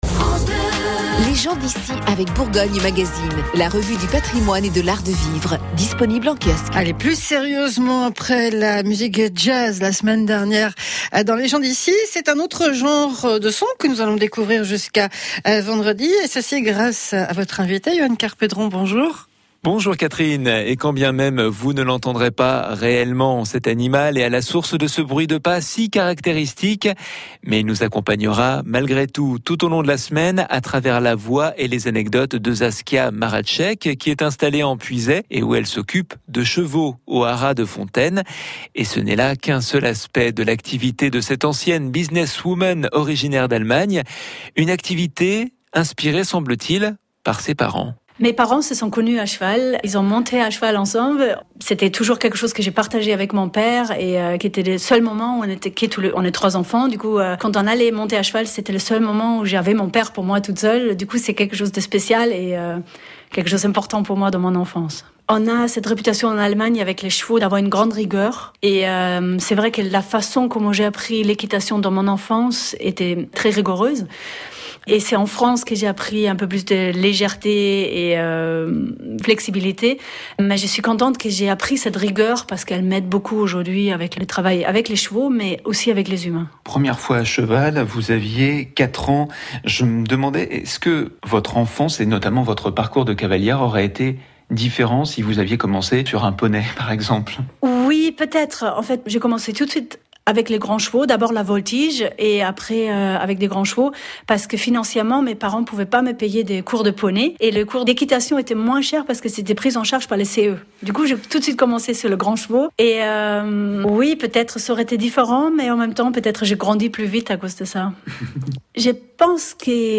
Série d’interviews sur France Bleu Auxerre